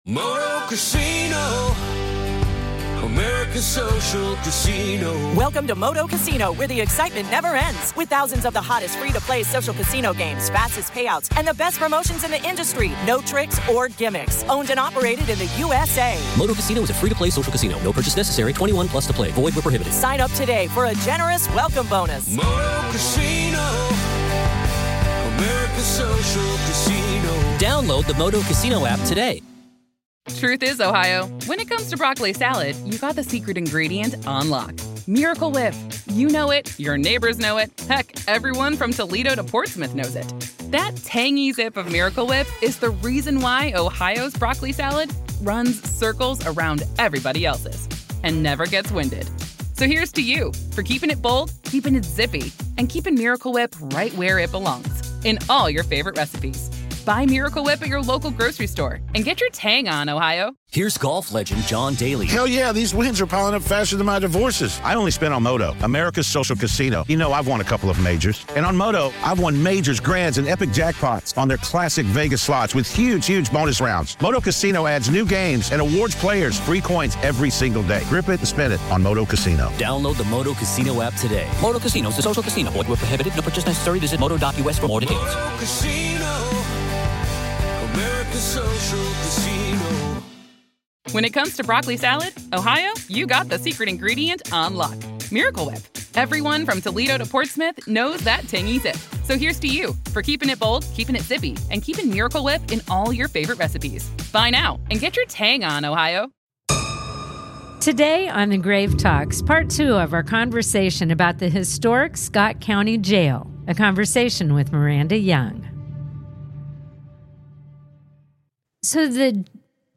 This is Part Two of our conversation